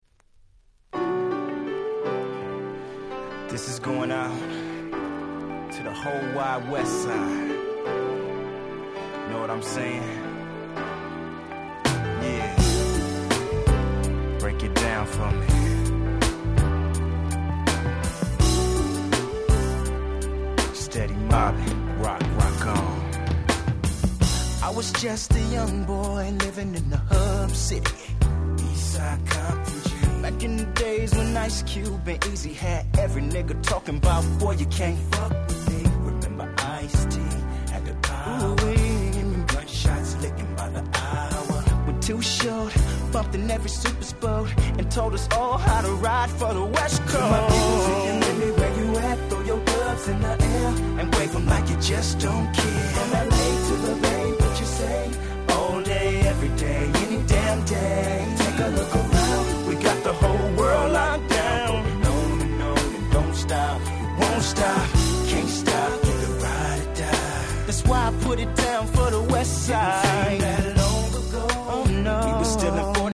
98' Nice R&B/West Coast.